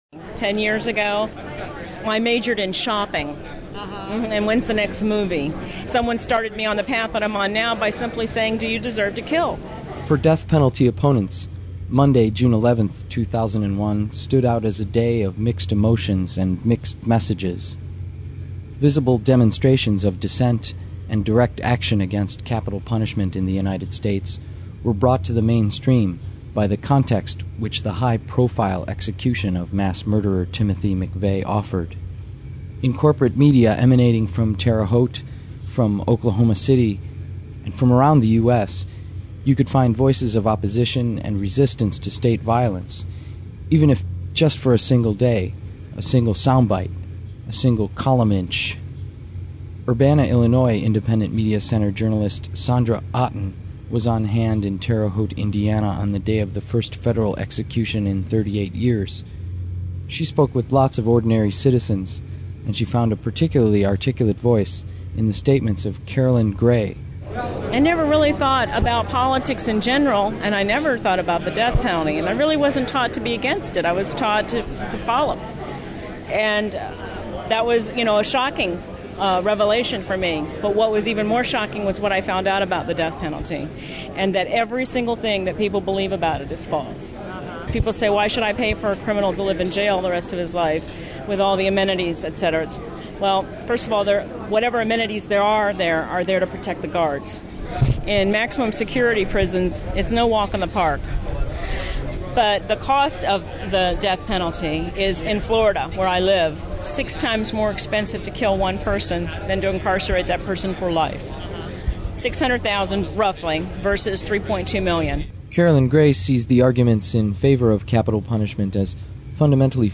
same as previous, only better audio quality